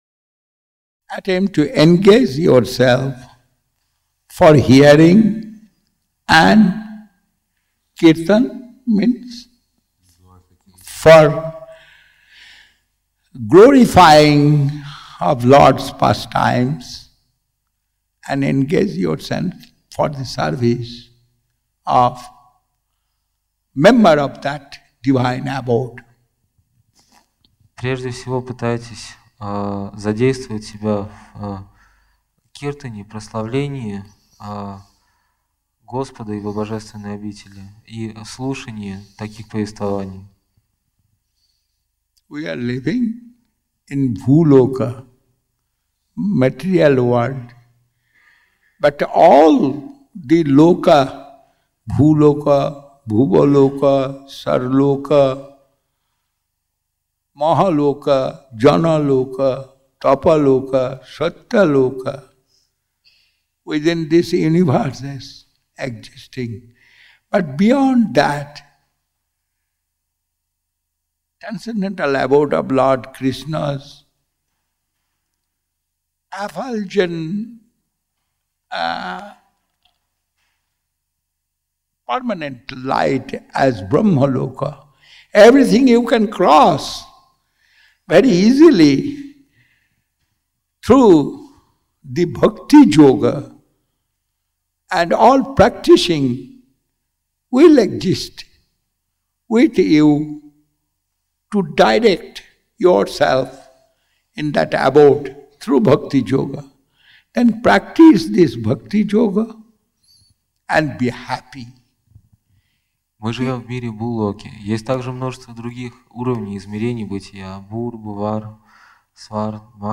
Место: Культурный центр «Шри Чайтанья Сарасвати» Москва
Проповедь